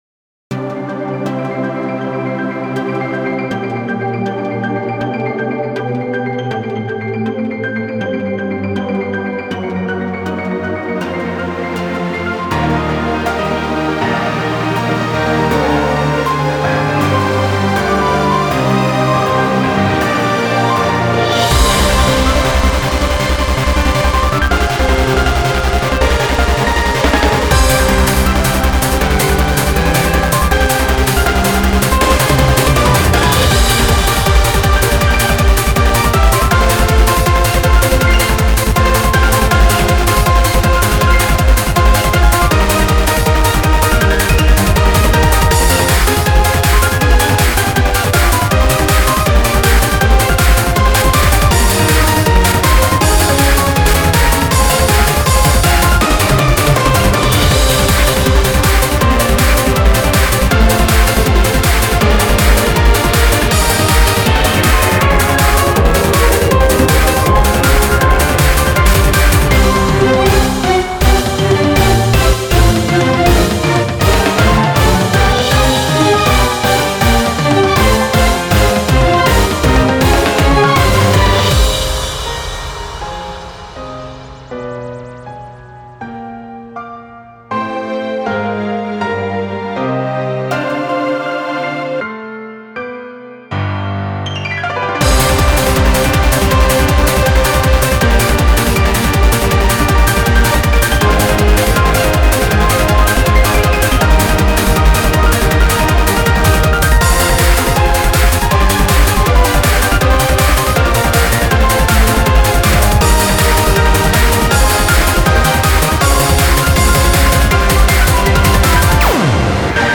BPM160